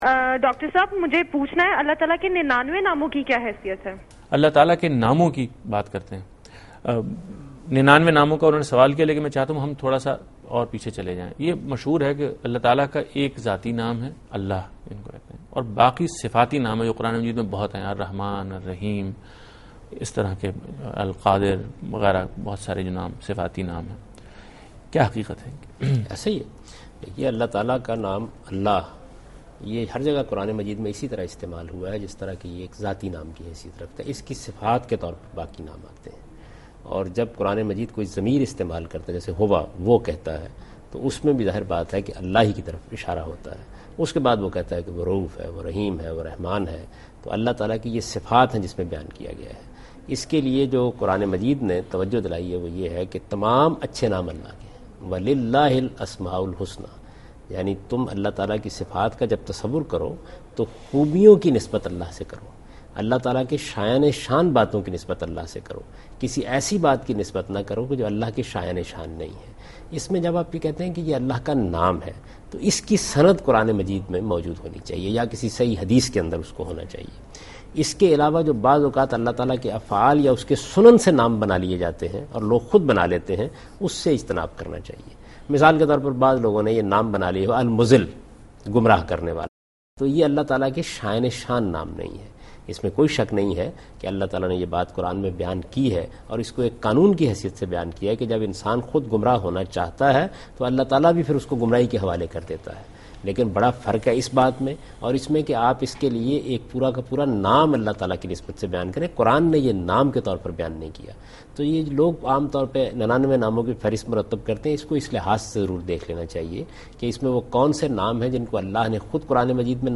Category: TV Programs / Dunya News / Deen-o-Daanish / Questions_Answers /
دنیا نیوز کے پروگرام دین و دانش میں جاوید احمد غامدی ”اللہ تعالیٰ کے ننانوے نام“ سے متعلق ایک سوال کا جواب دے رہے ہیں